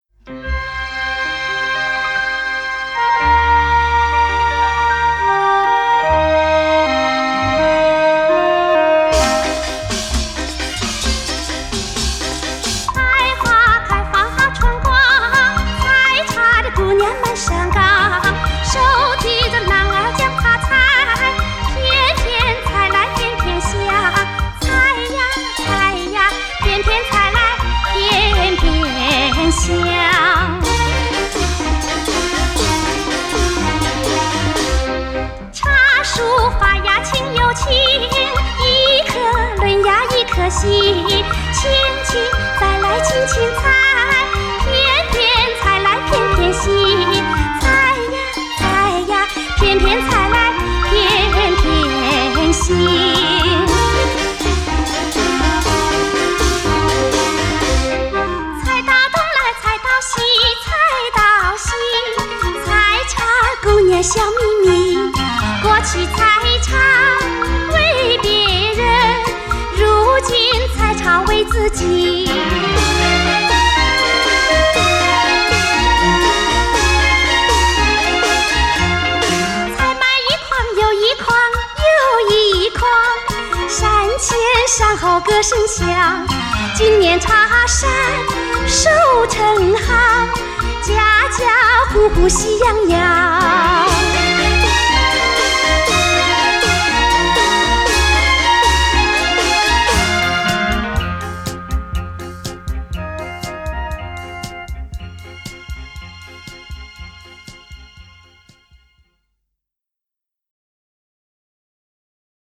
著名歌唱家合辑